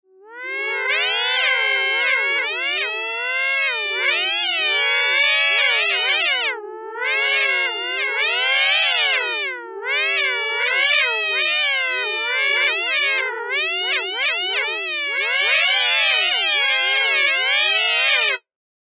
Нажата одна клавиша, звучит один осциллятор, одним ЛФО типа S&G модулировался питч и фильтр.